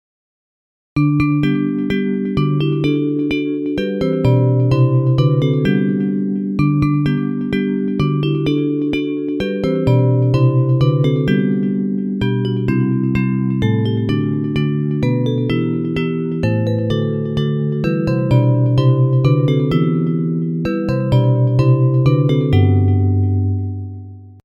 Bells Version
Music by: Polish carol;